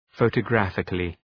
Προφορά
{,fəʋtə’græfıklı}